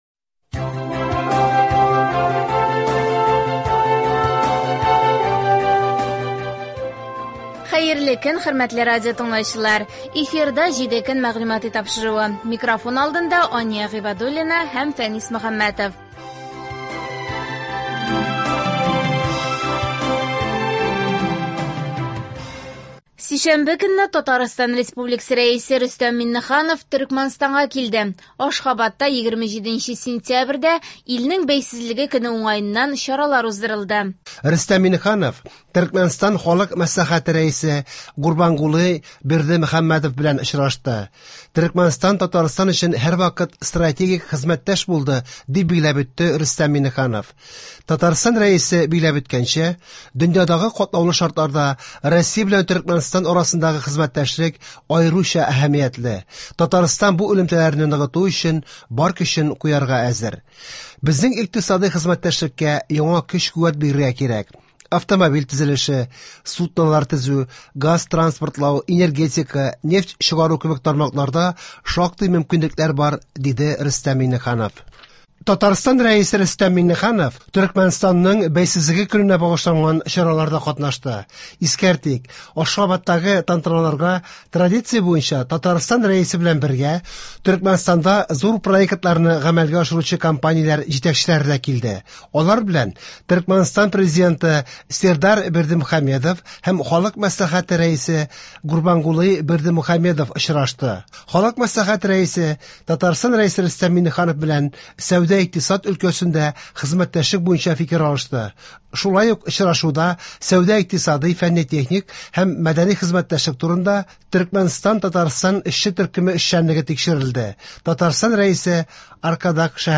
Атналык күзәтү.